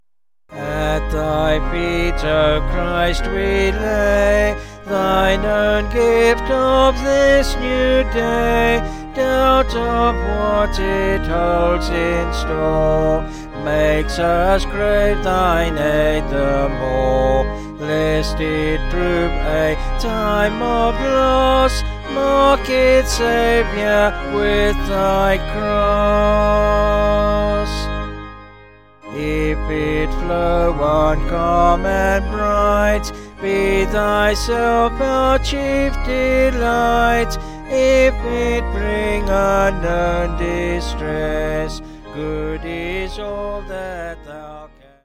(BH)   5/Ab
Vocals and Organ